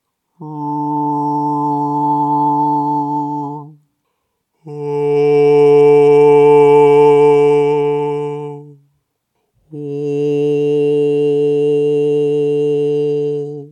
There are three possibilities to get to the fith-sound :
1. Bass sings 6th harmonic in overtone singing style. Sounds flute-like two octaves and a fifth higher as a separate instrument. This doesn’t serve as a stabilizing fundament but gives the piece a completely new character.
2. Bass sings /o/ with an emphasised 6th harmonic in singing phonetics style, which doesn’t sound like overtone singing, thus not changing the all over sound, but still giving the impression of a present fifth. (the best?)
3. Bass sing 3rd harmonic with NG-technique. Sounds quite (too) dark as a vowel.
fifth-effect-from-bass.mp3